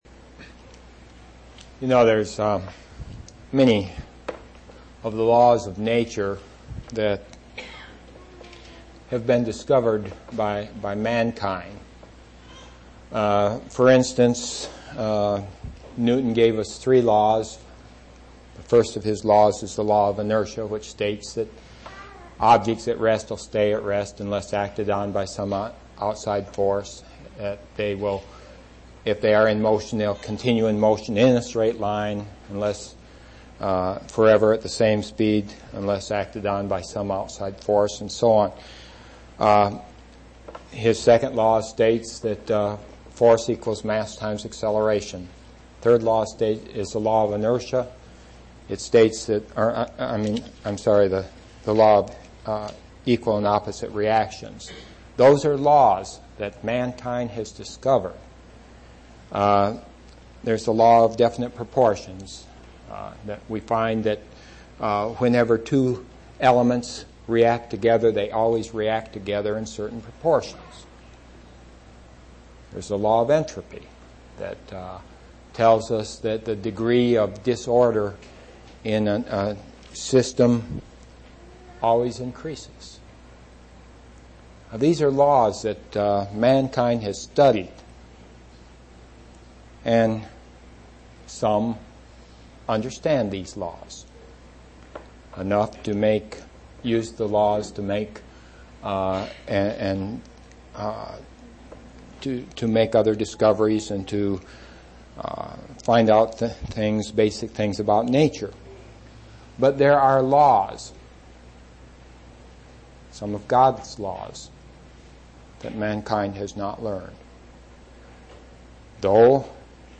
9/29/1991 Location: East Independence Local Event